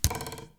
Arrow Impact.wav